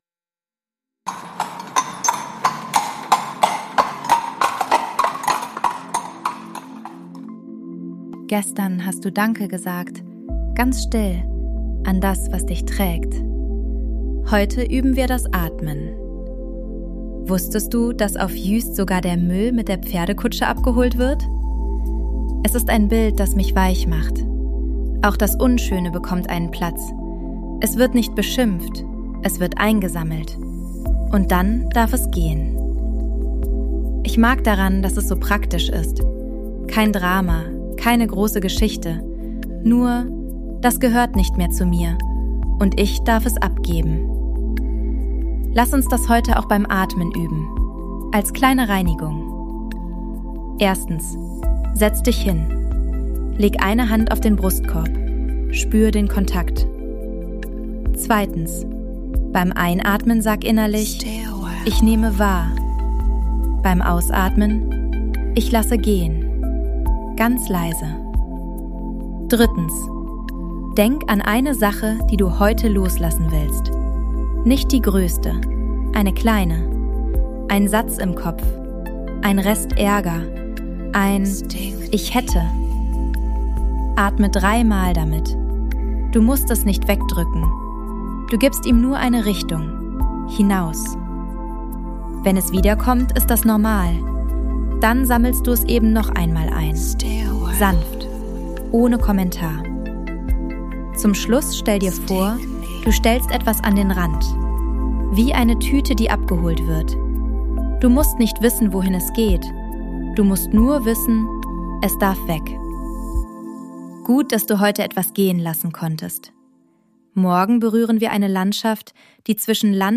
Kurverwaltung Juist Sounds & Mix: ElevenLabs und eigene Atmos